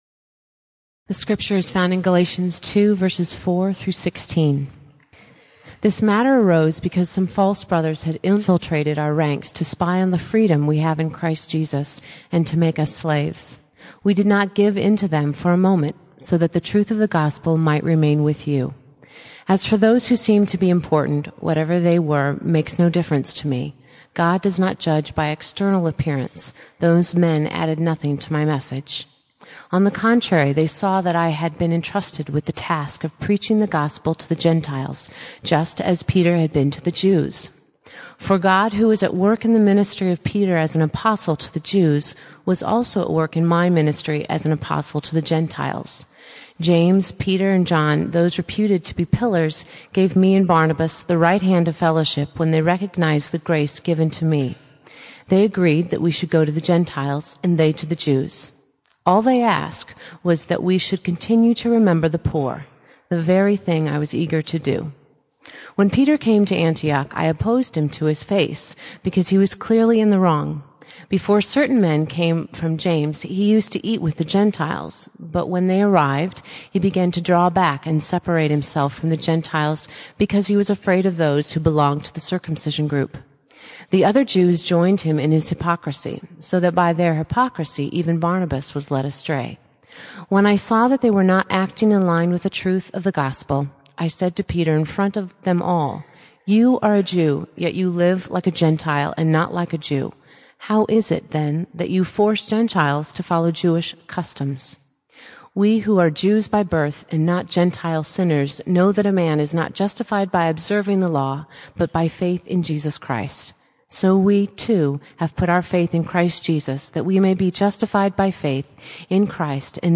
--- For more on truth, freedom, and the liberating power of Christ, I suggest listening to a sermon by Tim Keller based on Galatians 2:4-16 , found here .